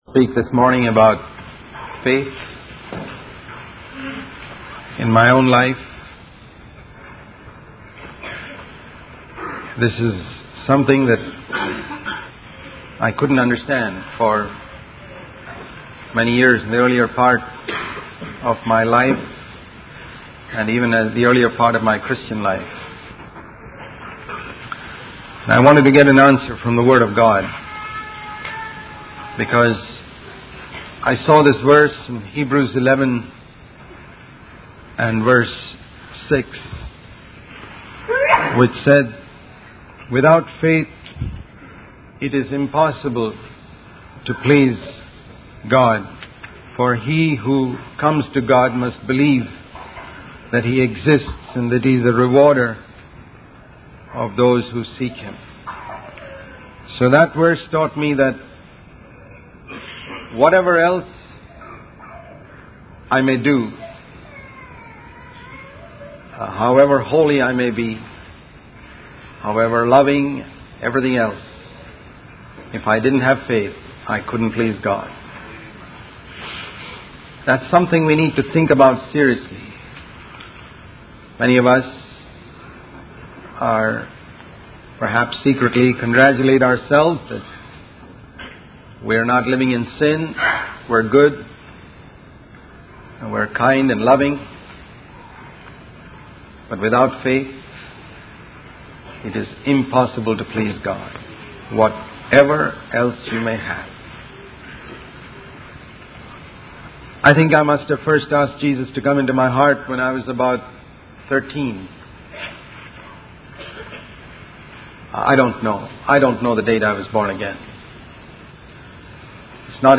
In this sermon, the preacher emphasizes the importance of trying and failing in order to learn valuable lessons.